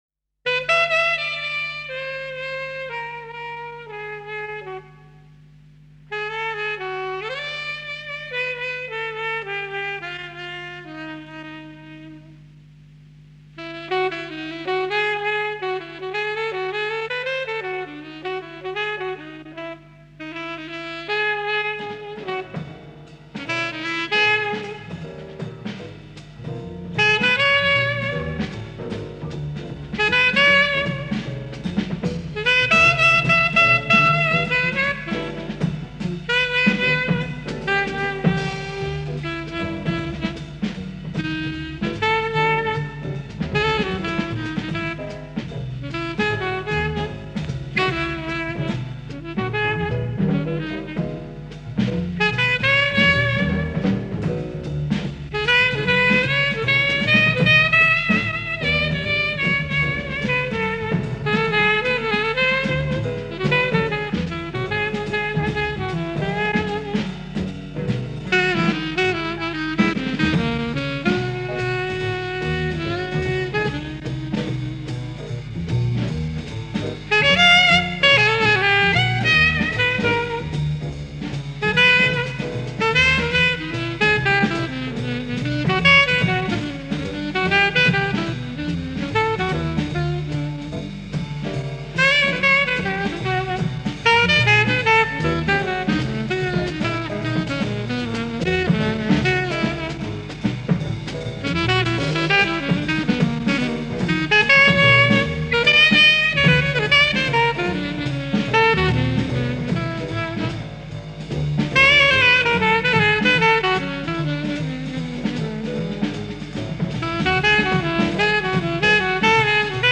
Live at Philharmonie Hall, Berlin
Jazz
drummer
bassist